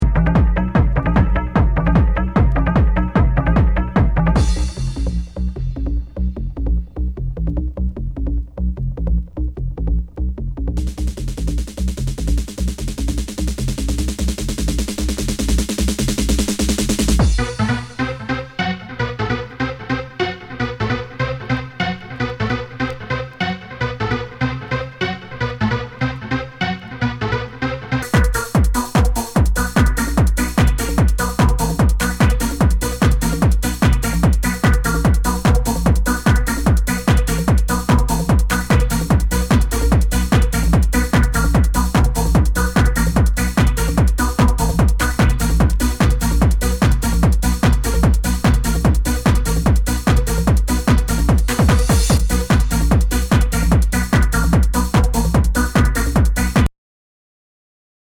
[ Genre ] HOUSE/TECHNO/ELECTRO